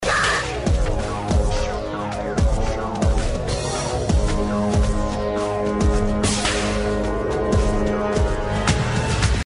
The track sounds like a trance/techno, I wish I had more info.
P.S ignore the U2 background garbage music.